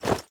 Minecraft Version Minecraft Version snapshot Latest Release | Latest Snapshot snapshot / assets / minecraft / sounds / item / armor / equip_gold5.ogg Compare With Compare With Latest Release | Latest Snapshot
equip_gold5.ogg